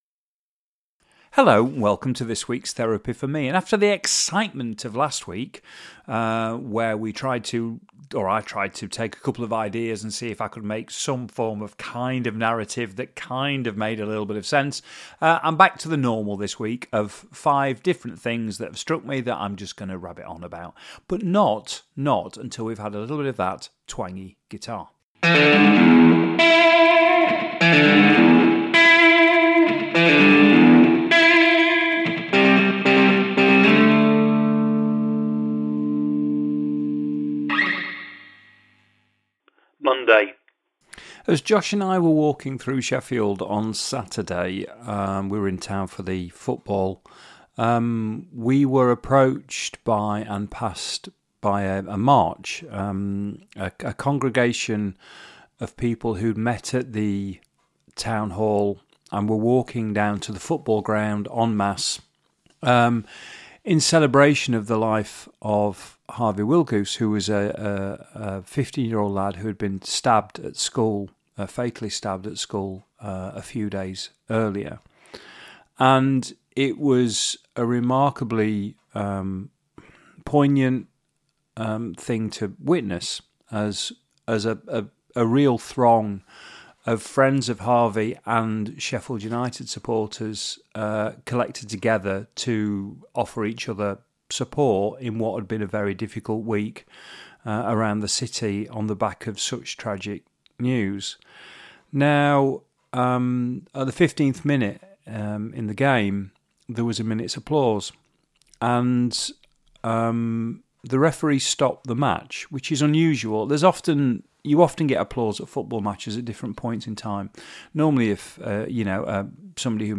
It started out as a mechanism for me to clear my head, with the hope that by saying stuff out loud it would act as a little bit of self-help. It's remains loose in style, fluid in terms of content and raw - it's a one take, press record and see what happens, affair.